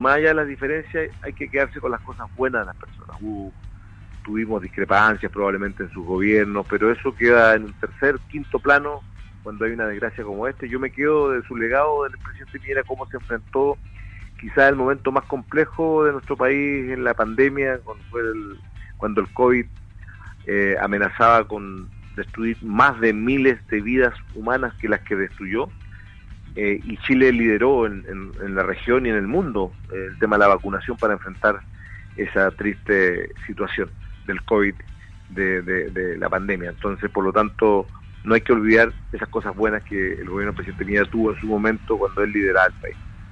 Quien fuera ministro de salud en el segundo gobierno de Sebastián Piñera, Emilio Santelices, en conversación con Radio Sago, comentó el impacto que significó la trágica muerte del ex mandatario y que enluta a todo un país.